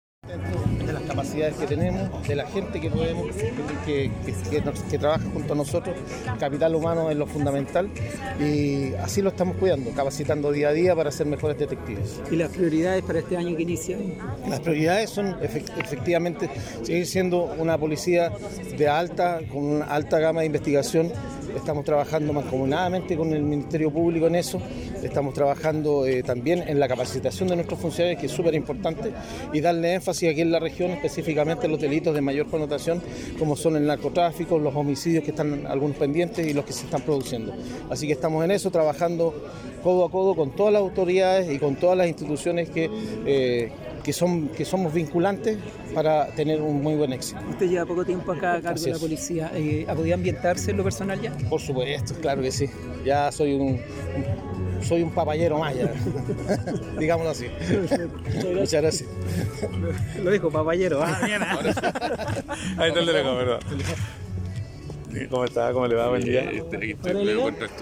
“Este es un acto que parece sencillo pero tiene un significado profundo especialmente para nosotros como detectives y nuestros agentes policiales. Cada día, al iniciar la jornada, el personal de guardia iza nuestro emblema nacional e institucional en cada complejo policial. El pabellón representa a los habitantes de nuestro país y el servicio de excelencia que entregamos por su seguridad a través de la investigación profesional de los delitos de alta complejidad, hasta rendir la vida si fuese necesario”, expresó en su intervención el prefecto inspector Ernesto León Bórquez, Jefe de la Región Policial de Coquimbo.
PREFECTO-INSPECTOR-PDI.mp3